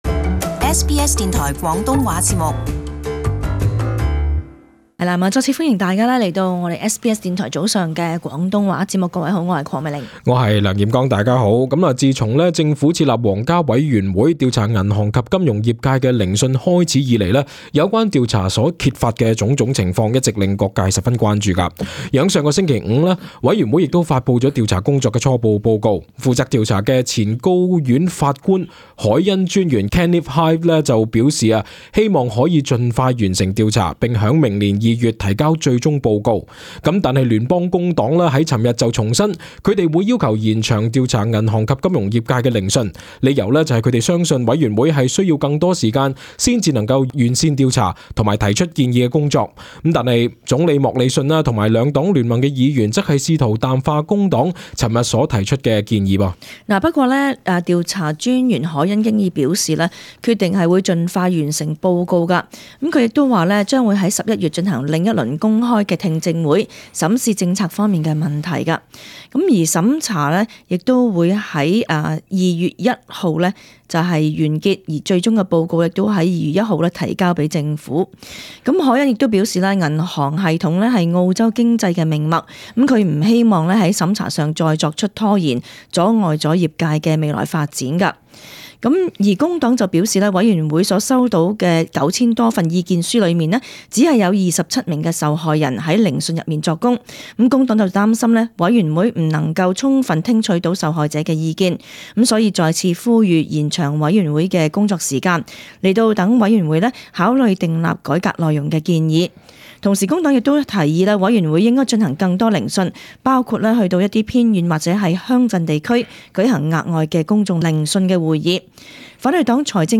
【時事報導】工黨要皇家委員會延長銀行業聆訊